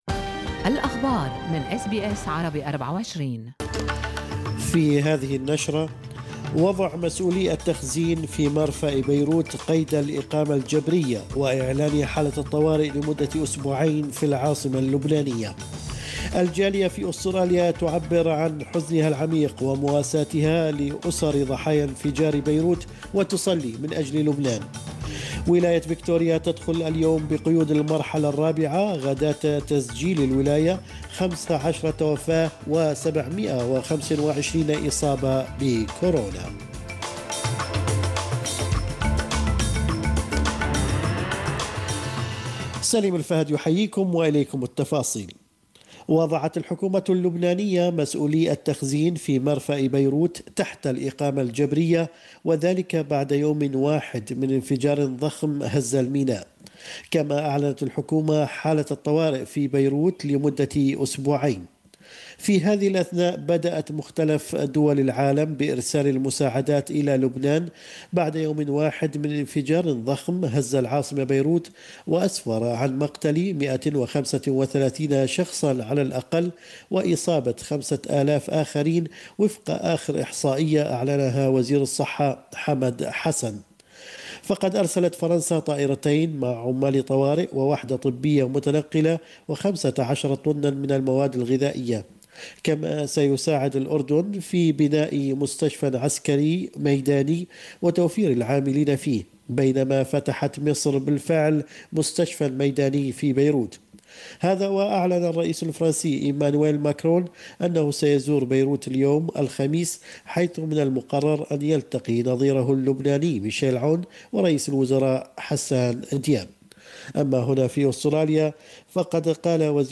نشرة اخبار الصباح 6/8/2020